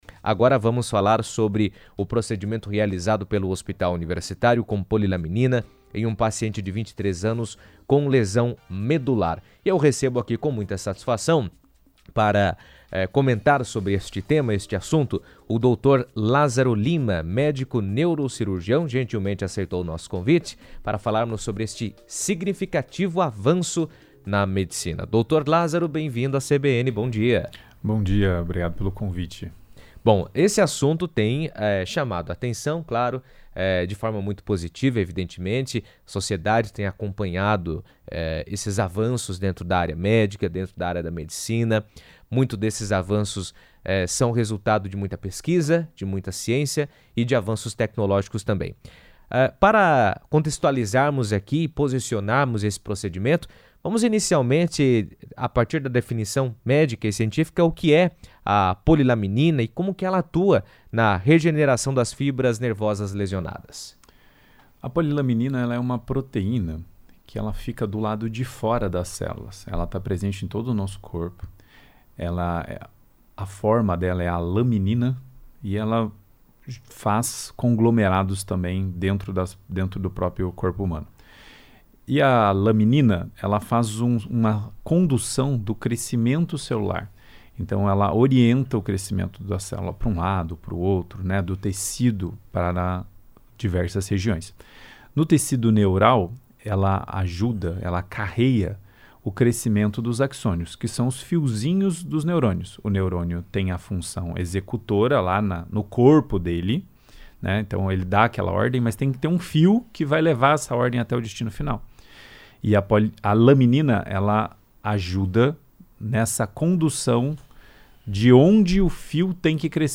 O procedimento foi autorizado pelo uso compassivo da Anvisa, que permite acesso a terapias experimentais quando não há alternativas eficazes e o paciente atende a critérios específicos. Em entrevista à CBN